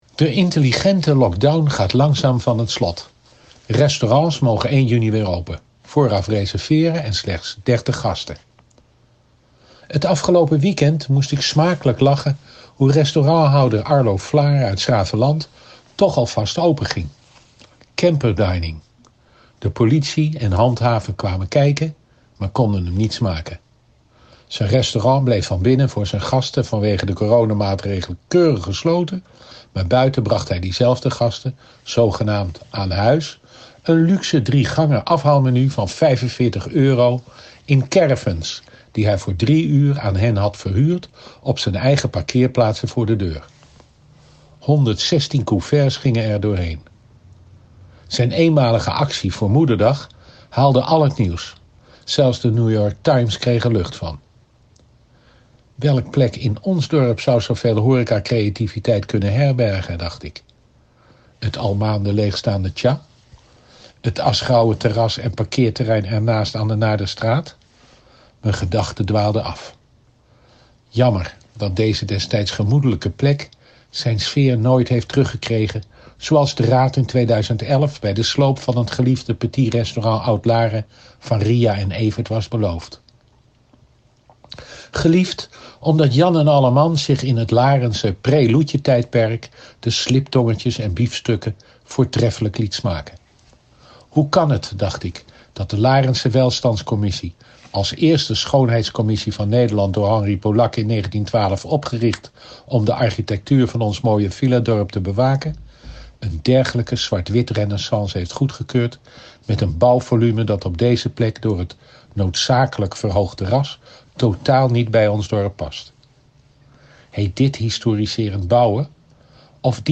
Column Leo Janssen
Leo Janssen, oud-wethouder en loco burgemeester van Laren, praat vandaag onder meer over horeca-creativiteit.